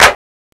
Index of /m8-backup/M8/Samples/Fairlight CMI/IIX/PERCUSN1
CLAP.WAV